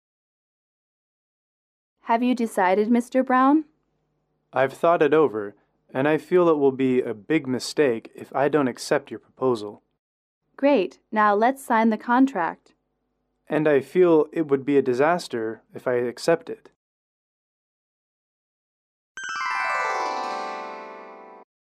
英语口语情景短对话26-2：拒签合同(MP3)